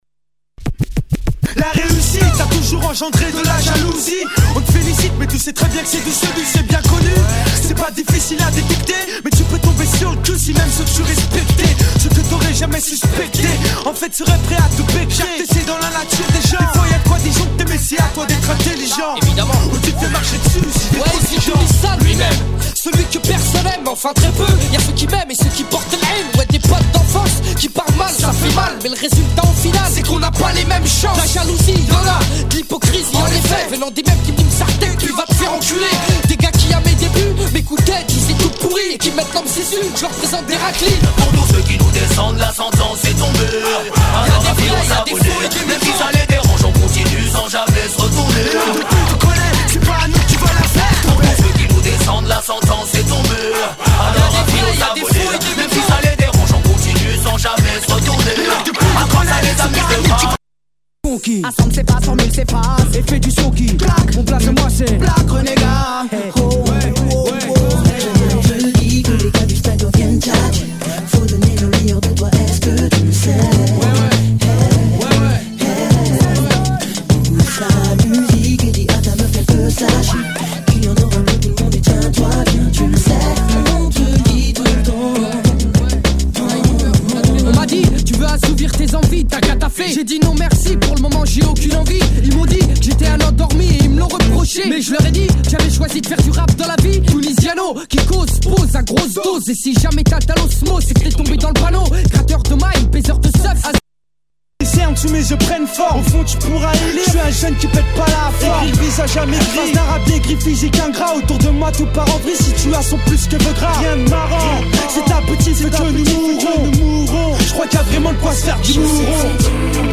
試聴 （複数の曲が入っています。試聴は別盤から録音したものです。）